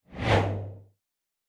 Fly By 03_1.wav